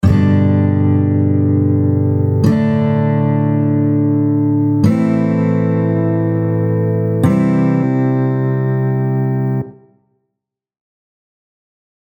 4 Chord progression – G, D5/A, Am, C.
33-progression-8.mp3